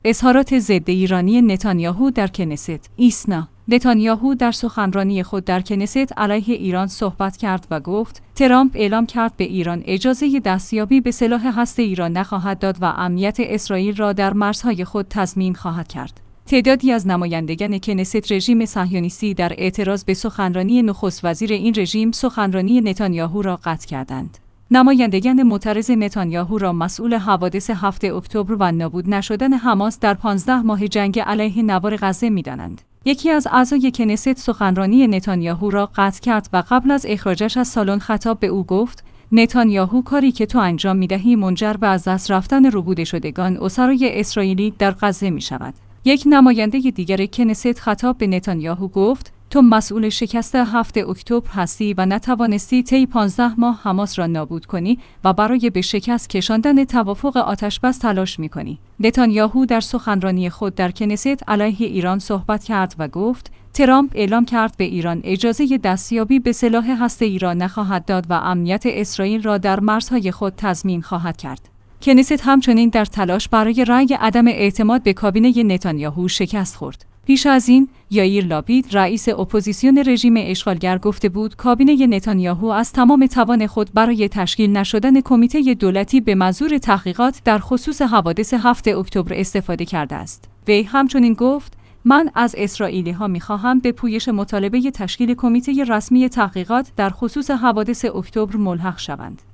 تعدادی از نمایندگان کنست رژیم صهیونیستی در اعتراض به سخنرانی نخست وزیر این رژیم سخنرانی نتانیاهو را قطع کردند.